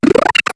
Cri de Poichigeon dans Pokémon HOME.